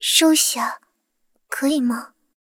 追猎者强化语音.OGG